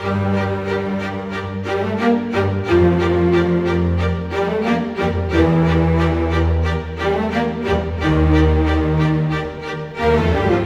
Rock-Pop 07 Strings 01.wav